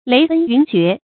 雷奔云谲 léi bēn yún jué
雷奔云谲发音